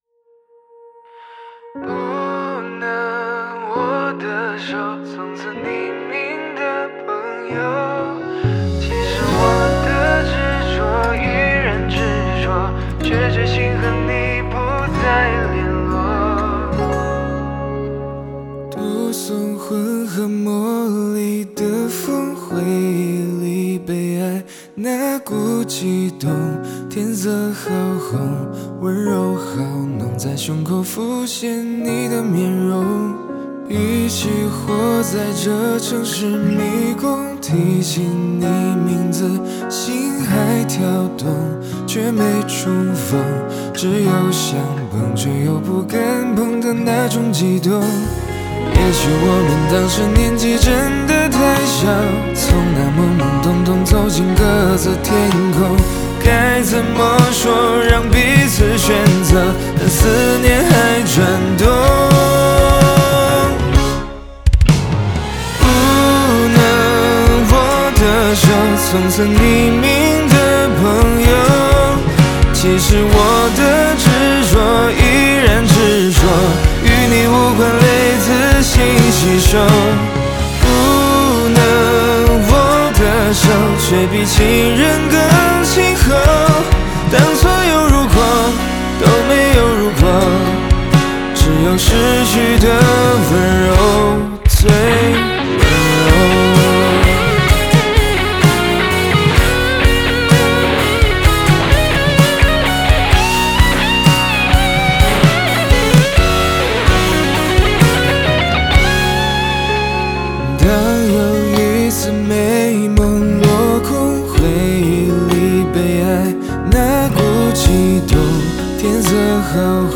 （此版本为正式授权翻唱作品，未经著作权人许可，不得翻唱，翻录或使用）